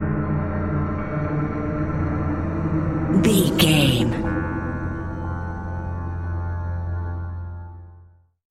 Fast paced
In-crescendo
Ionian/Major
F♯
industrial
dark ambient
EBM
drone
synths
Krautrock